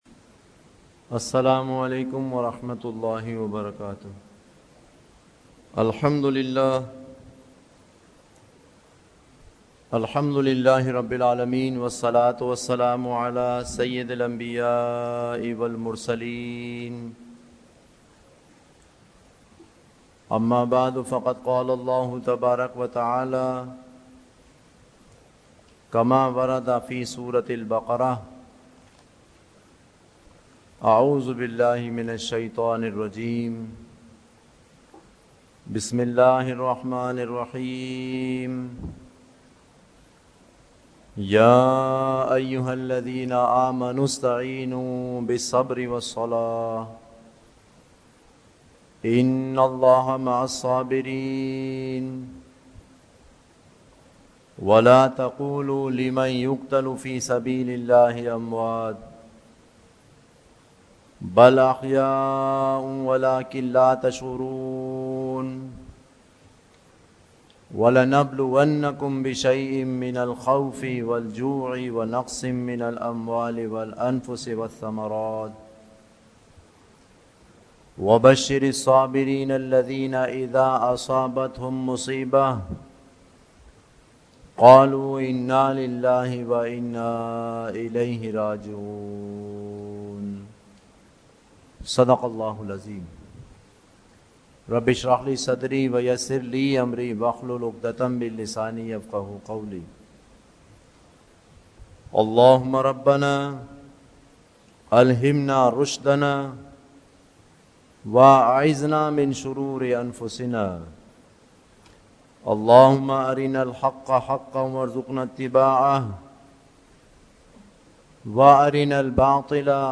Khutbat-e-Jummah (Friday Sermons)